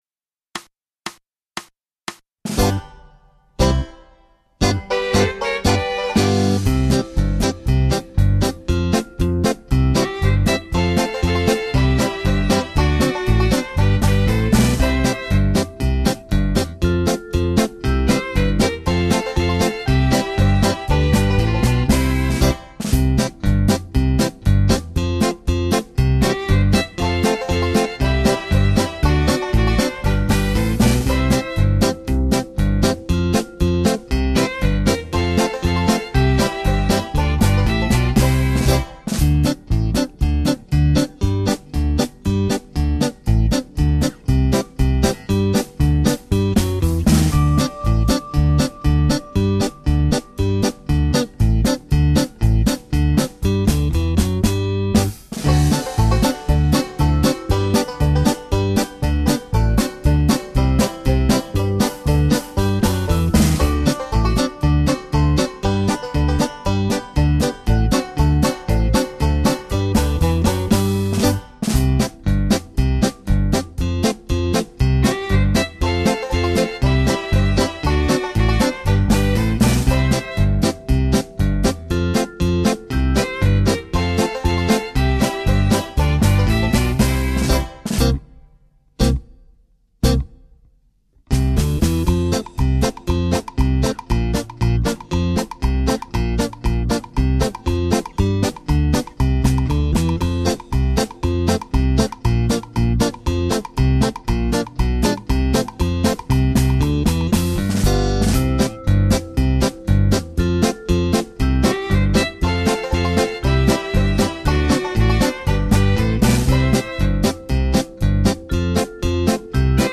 Genere: Polka
Scarica la Base Mp3 (2,57 MB)